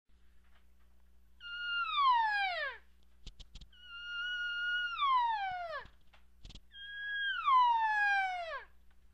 Cow Elk Long Mew Sounds Long Mew Cow Elk make the same sounds as the calves. The difference is the lower pitch and longer duration than the calf sounds. The Long Mew is longer than a regular mew with a little more of a whiney sound to it.
Long_Mew22.wma